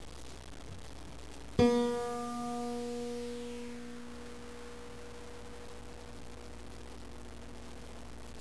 Tuning the Guitar
5. The second string sounds like
string2.wav